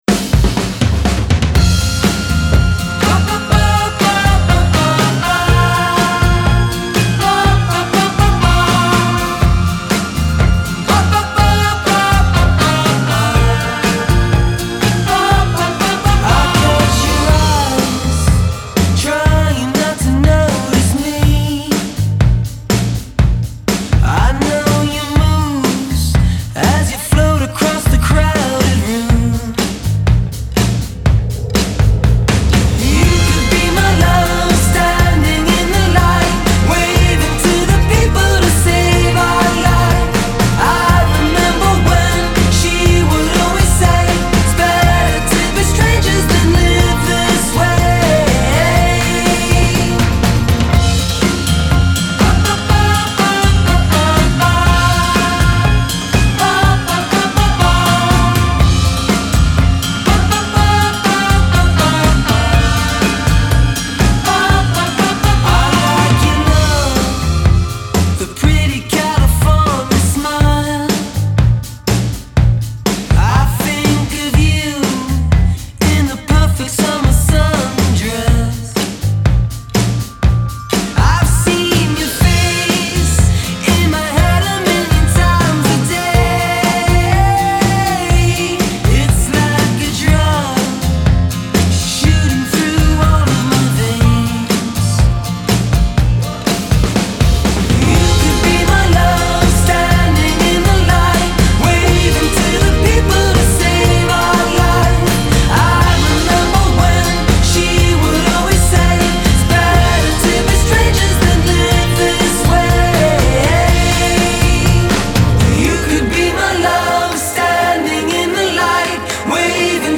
Los Angeles-based trio
thrums with warmth and joy
indie pop
With a thumpy, head-bobbing core
opens with a burst of happy wordless vocals